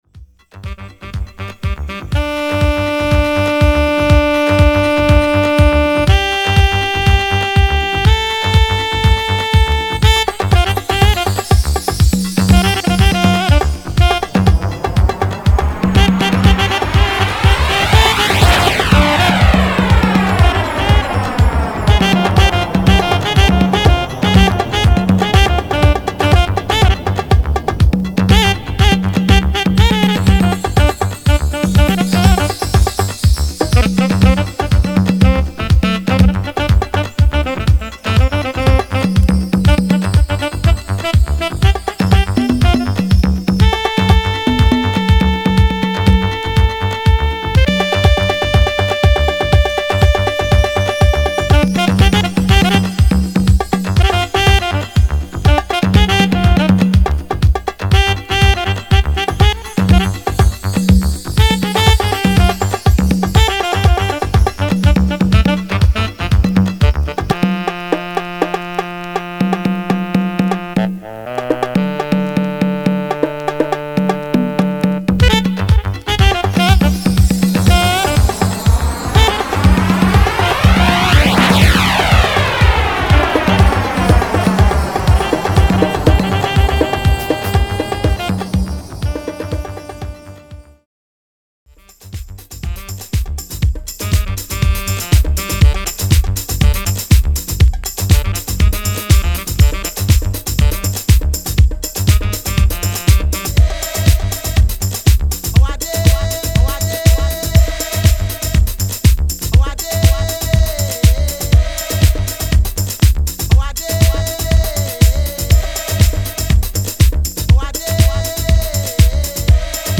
JET機が飛び去って行くSEに当時のFLOORは熱狂しました。